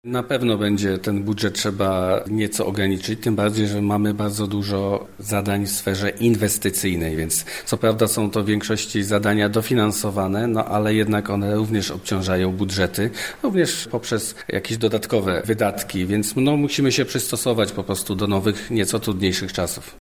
mówi Olaf Napiórkowski, zastępca burmistrza Żar